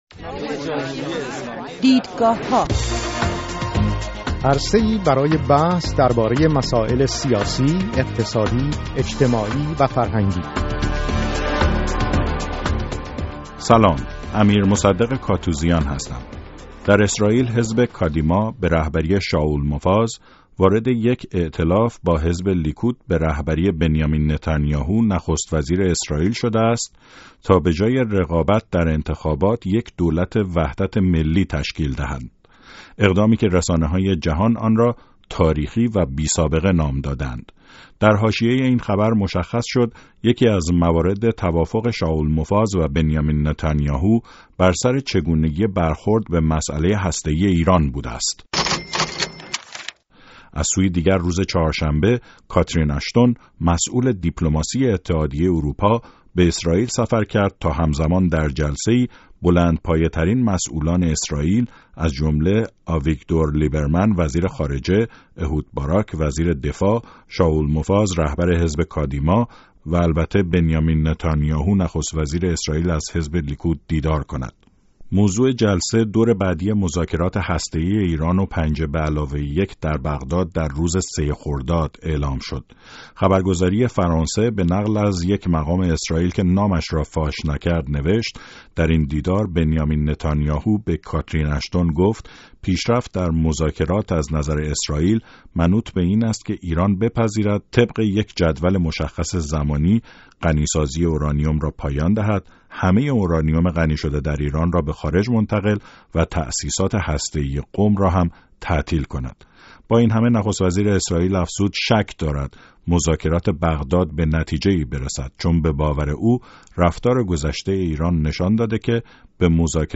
از جمله این پرسش‌ها را با سه میهمان برنامه دیدگاه‌ها در میان گذاشته‌ایم